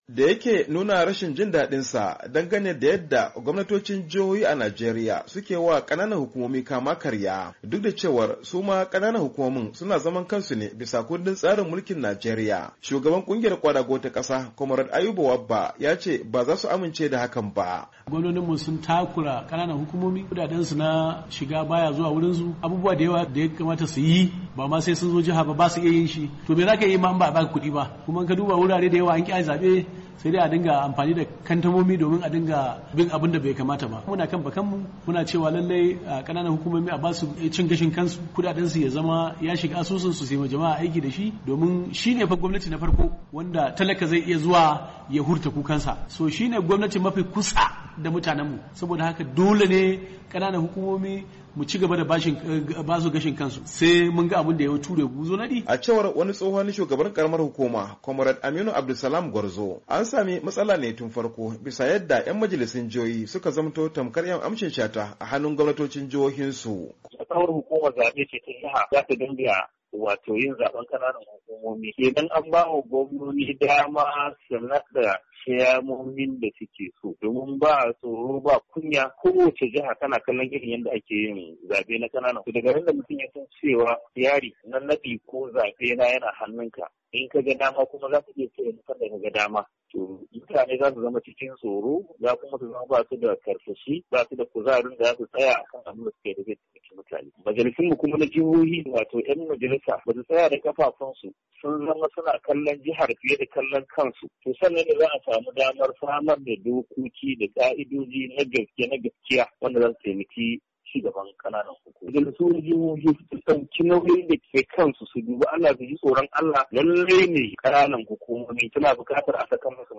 WASHINGTON D.C. —
Da yake nuna rashin jin dadinsa dangane da yadda gwamnatocin jihohi a Najeriya ke yiwa kananan hukumomi kama karya, shuagaban kungiyar NLC, Kwamarad Ayuba Wabba, ya ce ba zasu amince da hakan ba.